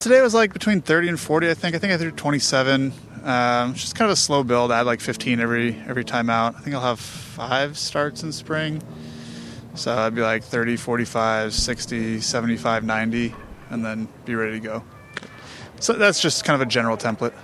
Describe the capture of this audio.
The Los Angeles native addressed reporters from the Dodgers spring training site in Glendale, Arizona, Tuesday.